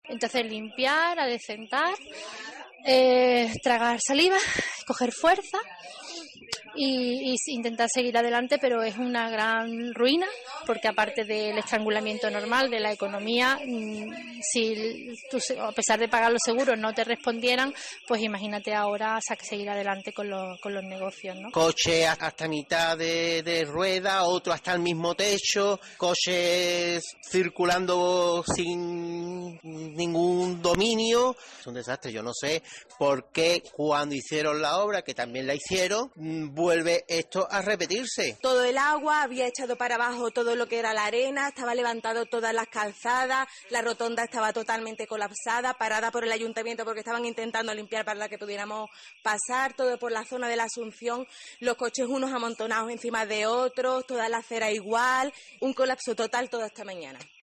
Varios de Jerez de la Frontera en Cádiz cuentan a COPE cómo han vivido las inundaciones provocadas por las intensas lluvias